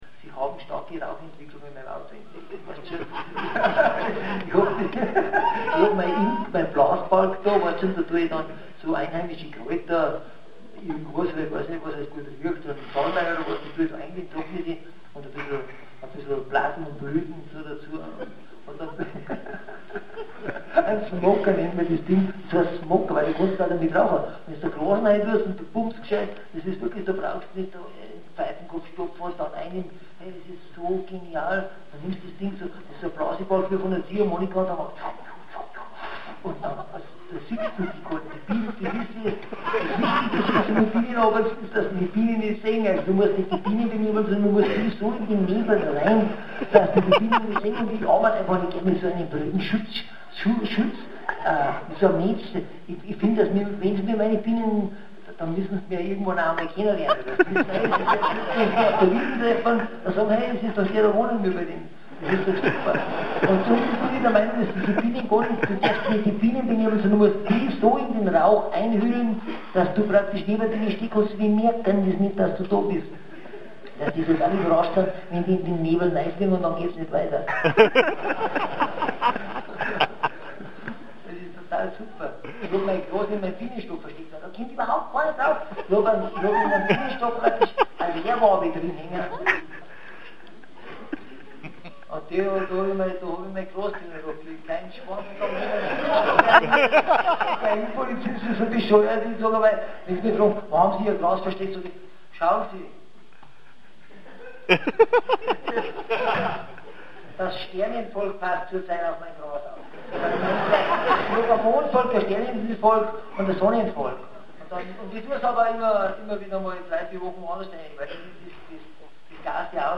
Da isser ja... sie waren zu dritt.
Hab aber gefilmt und hier 5 Minuten Ton rausgeschnitten
Am Ende waren es nur 7 Lieder auf 3 Stunden.